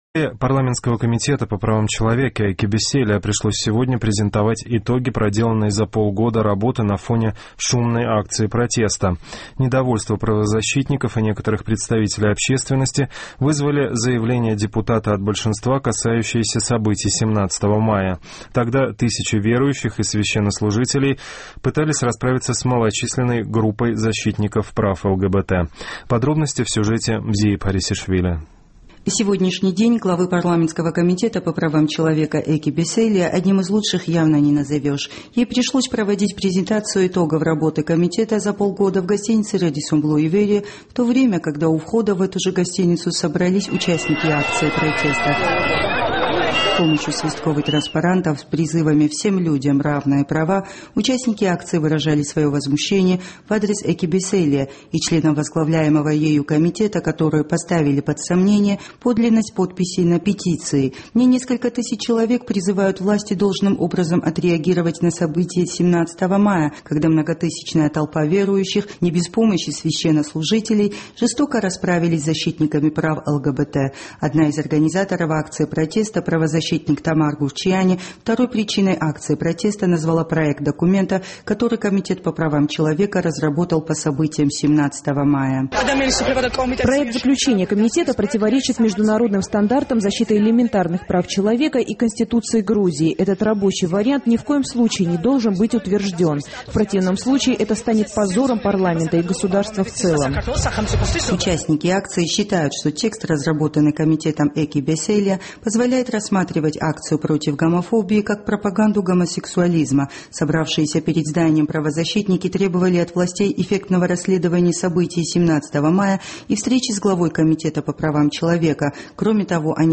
Глава парламентского комитета по правам человека Эка Беселия провела презентацию итогов работы комитета за полгода на фоне шумной акции протеста. Недовольство общественности вызвали заявления депутата от большинства, касающиеся событий 17 мая.